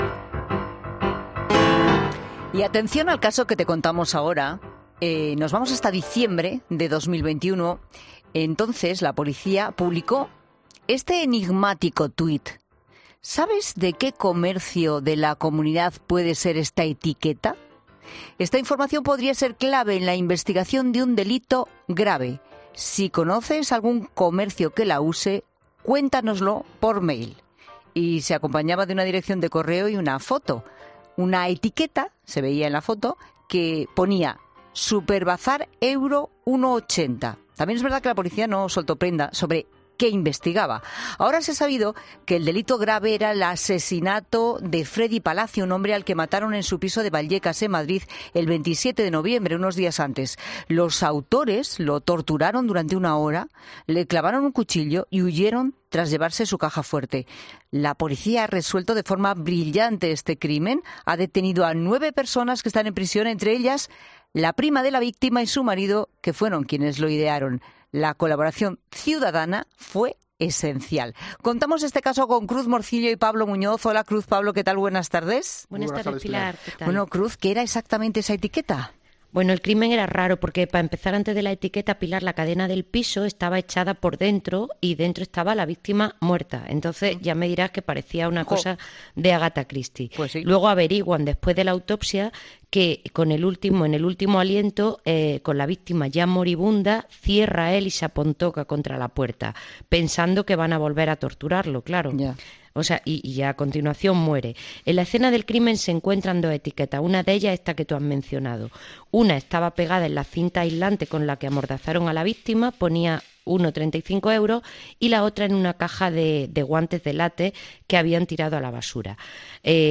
han comentado en 'La Tarde' las numerosas investigaciones que se desarrollaron en este caso